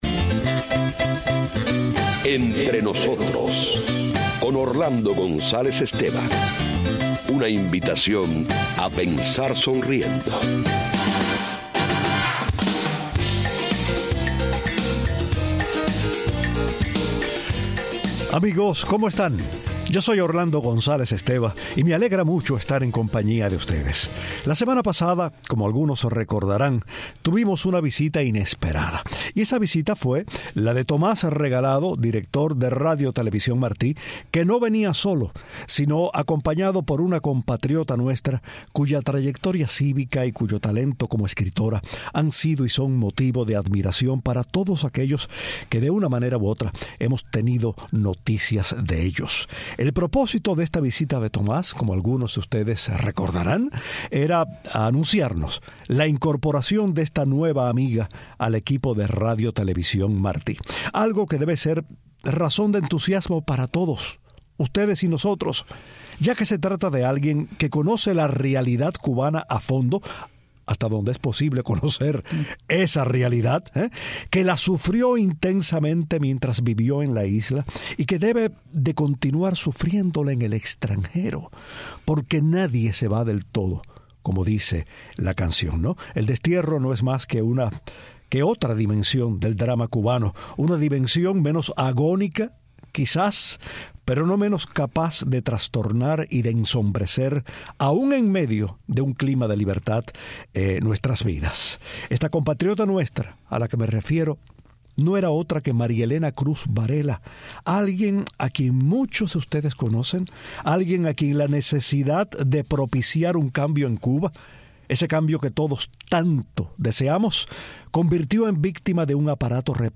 La destacada escritora y opositora cubana habla con emoción de algunos capítulos de su vida, de la necesidad de sobreponerse al miedo, de su intensa relación con la poesía y del mensaje que quiere llevar a la cubanos de la isla a través de su trabajo en Radio Televisión Martí.